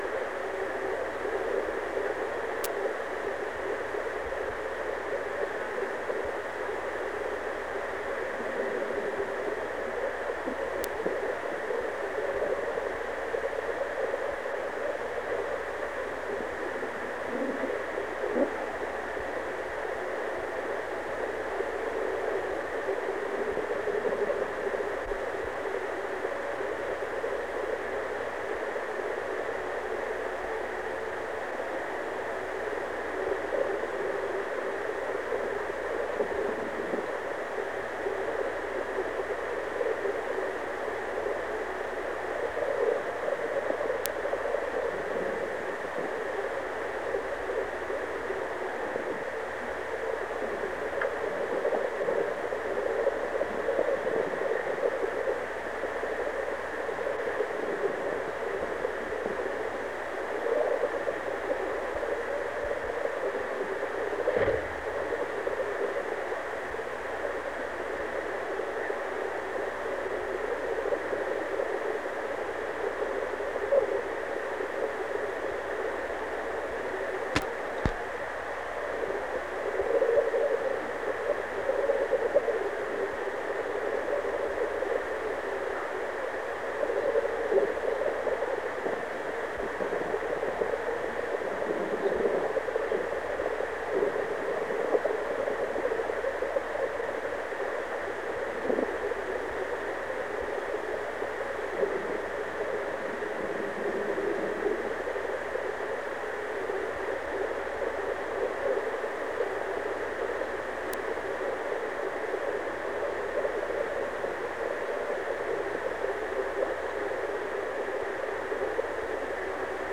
Secret White House Tapes | John F. Kennedy Presidency Meeting with Adlai Stevenson Rewind 10 seconds Play/Pause Fast-forward 10 seconds 0:00 Download audio Previous Meetings: Tape 121/A57.